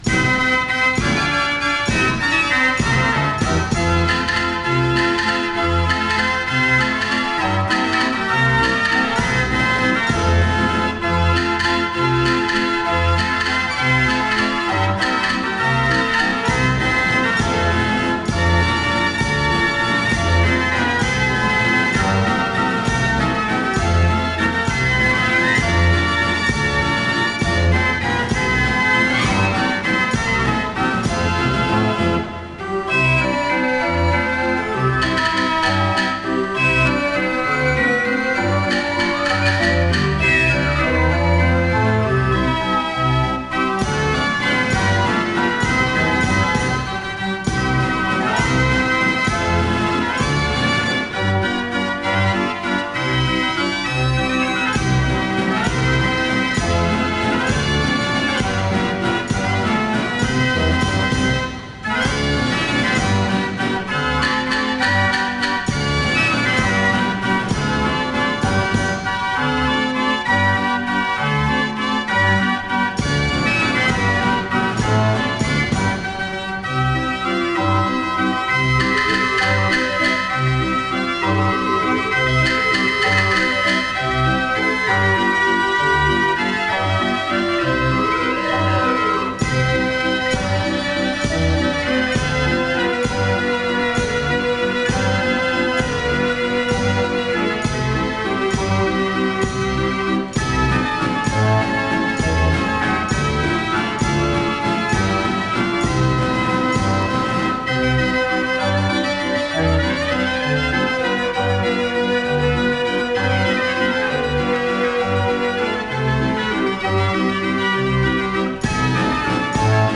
straatorgels